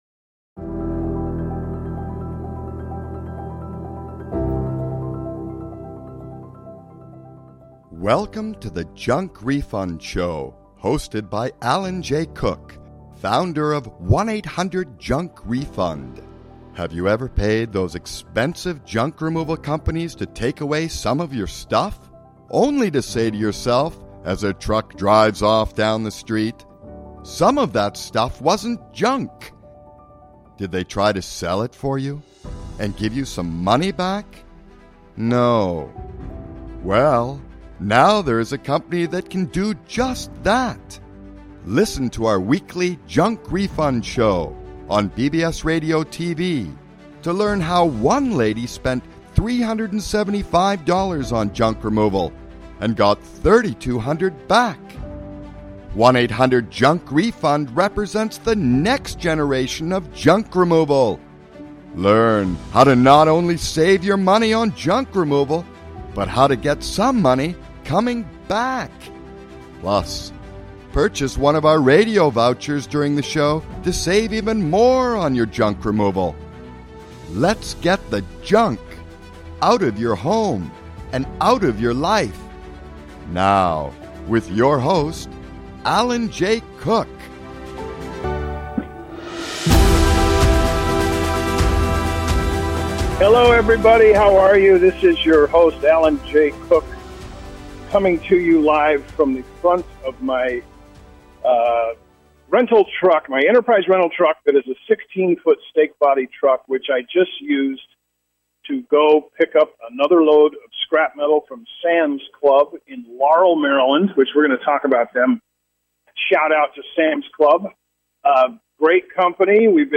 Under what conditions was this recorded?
Live from Enterprise Rental Truck, picking up load at Sam's Club.